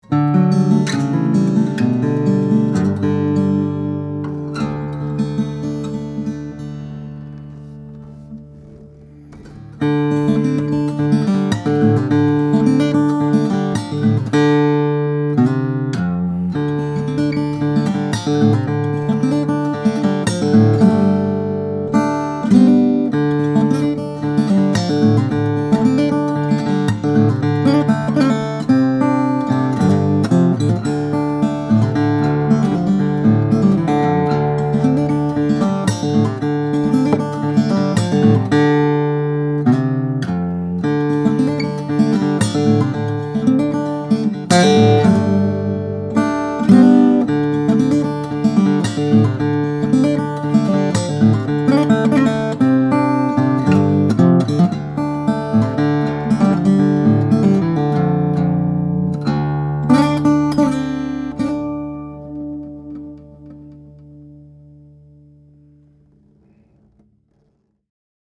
2010 Sobell Martin Simpson Signature, African Blackwood/German Spruce - Dream Guitars
This is a fantastic example from 2010 in African Blackwood and German Spruce with some honest play wear that tells us this guitar hasn’t been collecting dust…